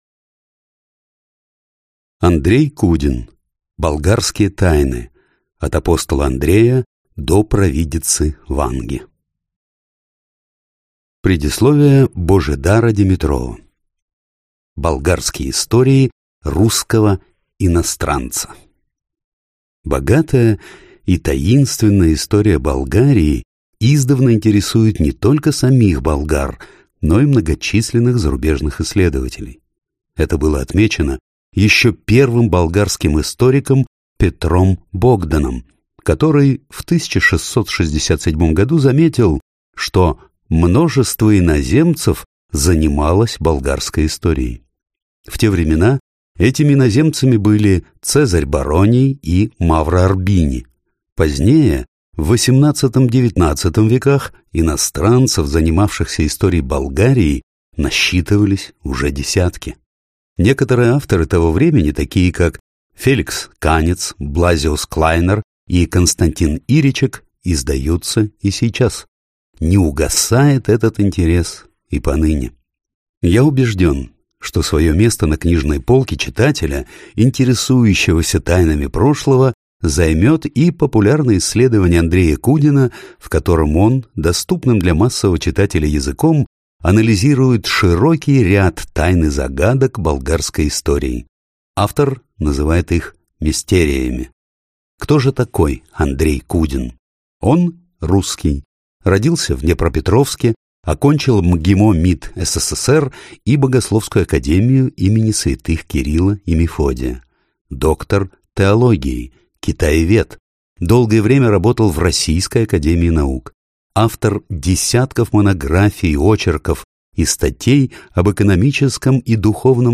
Аудиокнига Болгарские тайны. От апостола Андрея до провидицы Ванги | Библиотека аудиокниг